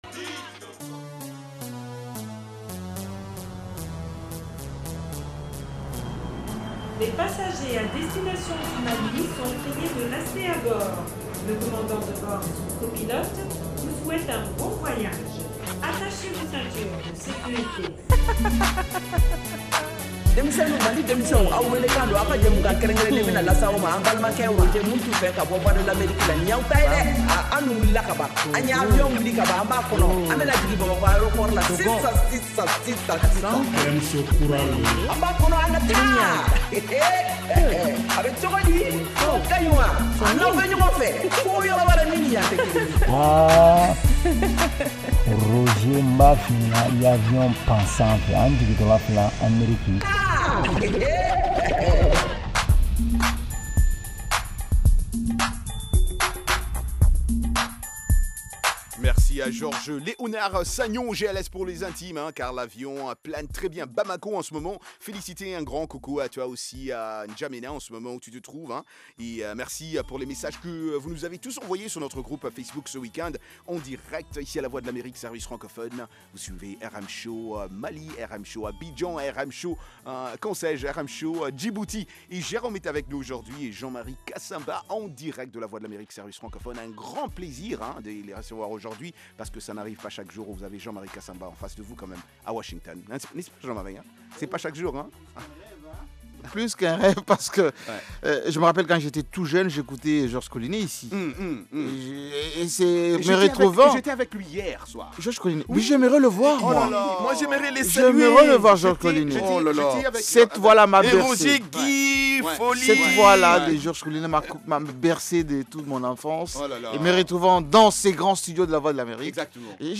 Emission quotidienne de musique et d’entretien avec les auditeurs.
propose une sélection spéciale de musique malienne et internationale